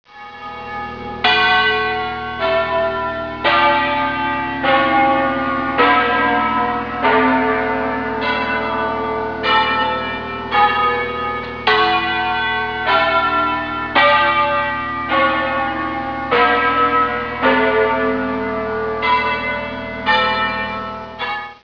In the tall (79.4m) tower, which was built between 1913 and 1925, is a ring of nine bells, tenor 90cwt (in Ab), making them the heaviest full circle ring in the world.
For a short .WAV file of these impressive bells (with the 1934 tenor), click